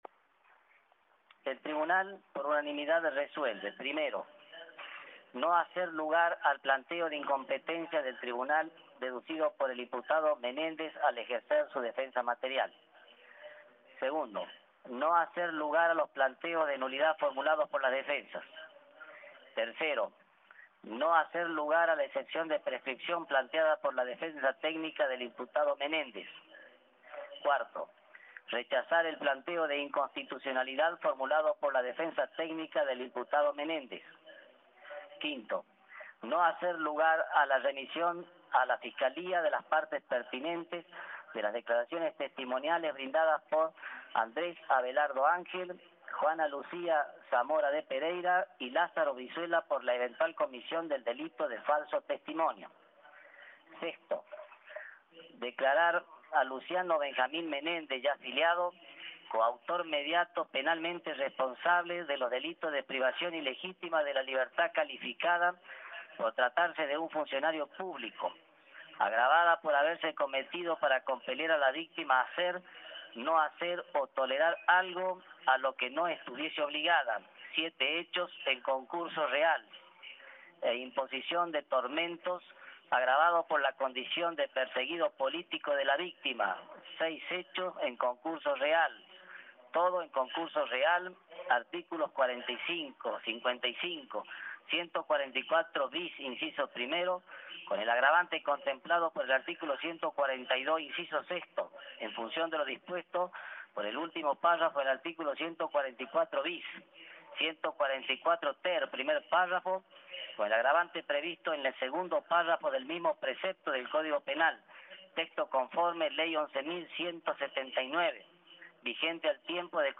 La lectura del veredicto por parte del Tribunal
El Tribunal Oral en lo Criminal Federal de La Rioja dio a conocer este viernes la sentencia en un juicio oral por crímenes de lesa humanidad cometidos en esa provincia durante la última dictadura.
5fd29-lectura-de-la-sentencia.mp3